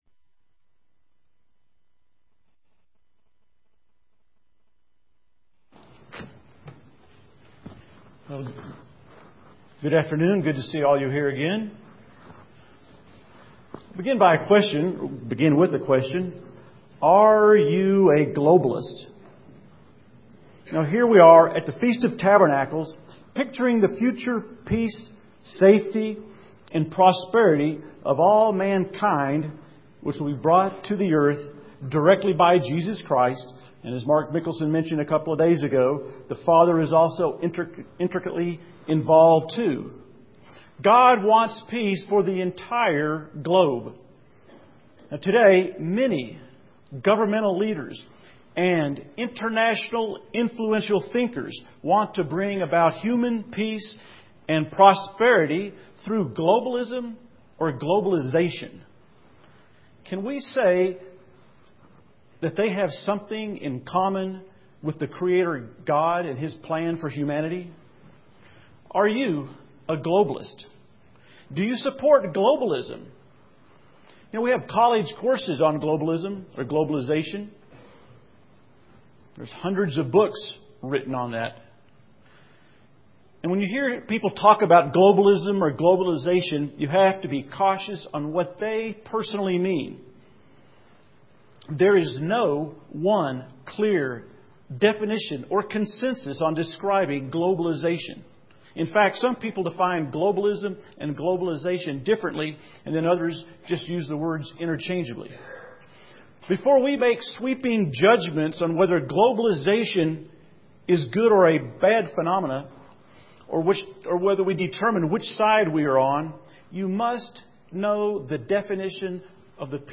This sermon was given at the Steamboat Springs, Colorado 2011 Feast site.